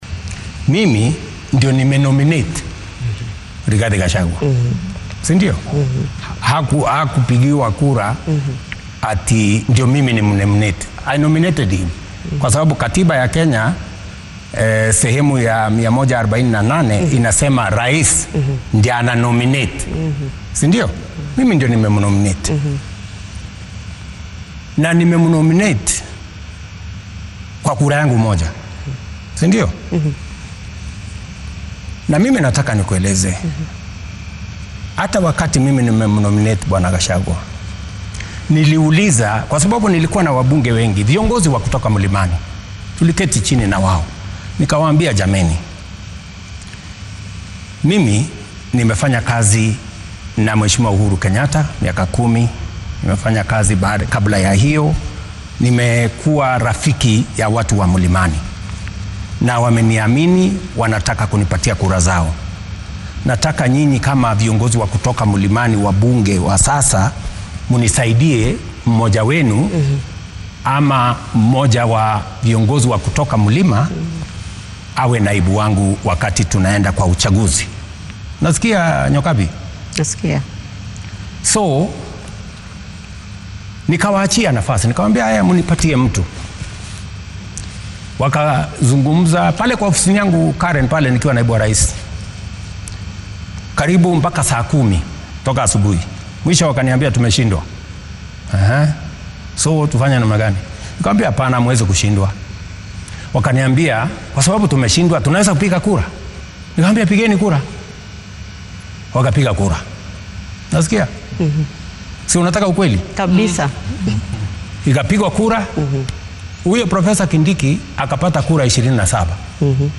Hoggaamiyaha qaranka oo xalay wareysi gaar ah siyay telefishenada maxalliga ah xilli uu ku sugnaa aqalka yar ee madaxtooyada ee deegaanka Sagana ee ismaamulka